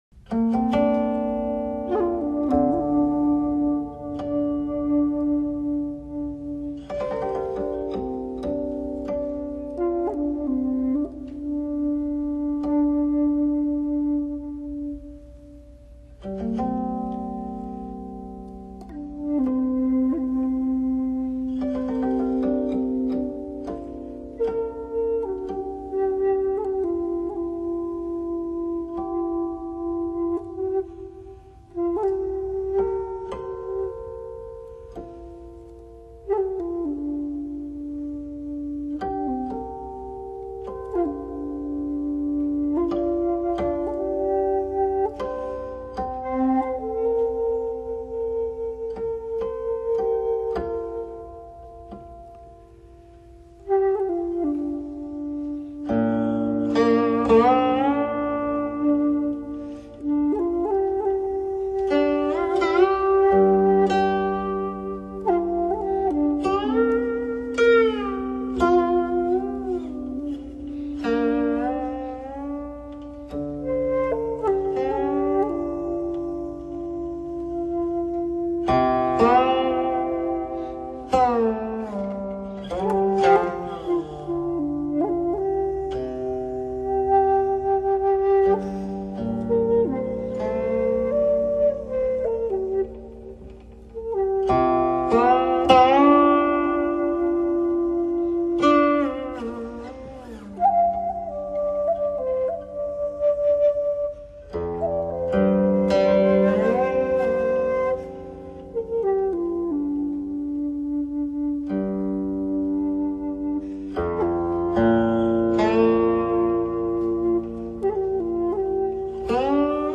音乐类型：民乐